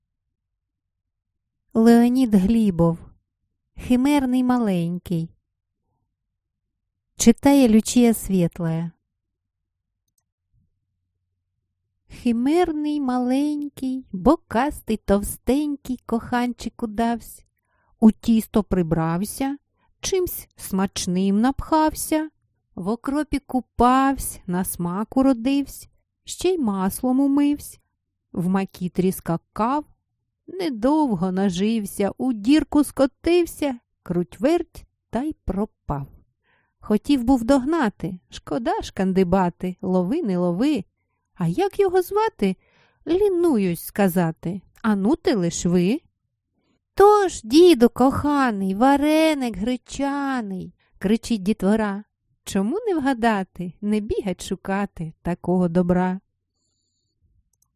Aудиокнига Загадки (Збірка) Автор Леонід Глібов